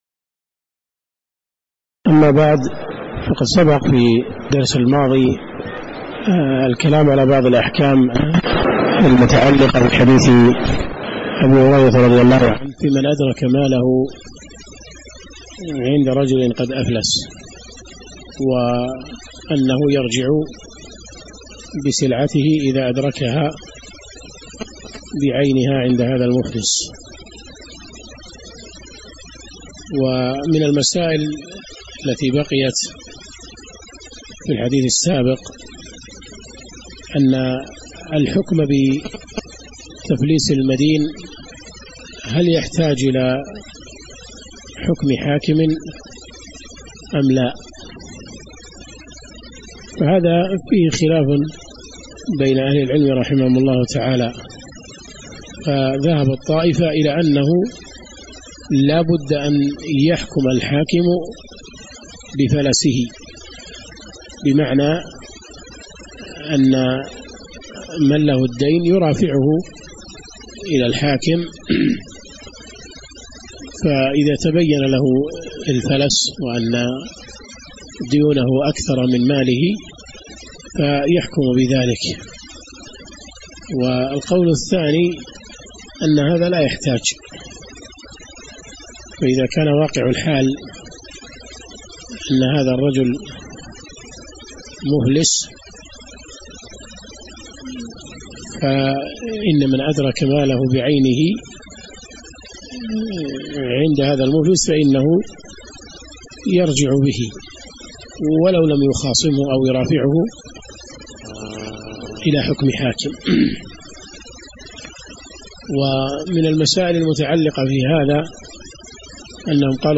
تاريخ النشر ٢٢ ربيع الأول ١٤٣٩ هـ المكان: المسجد النبوي الشيخ